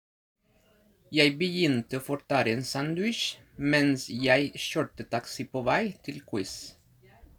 Well… Because as always I was curious about their lifestyles here in Norway, so I asked them a few questions and of course I recorded their voices reading that pangram to discover how they pronounce the Norwegian sounds!